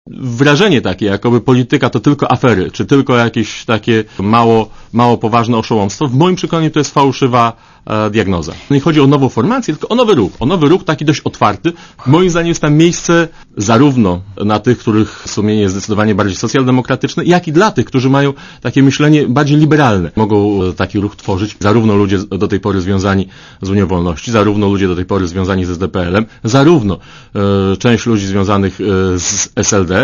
Jerzy Hausner może być liderem nowej formacji centrolewicowej - mówi w Radiu Zet Waldemar Dubaniowski, szef gabinetu prezydenta RP.